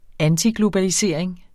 Udtale [ ˈanti- ]